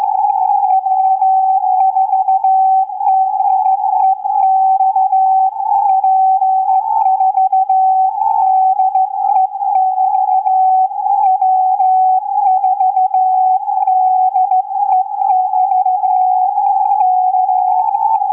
(dead air time between exchanges due to path delay edited to reduce file size)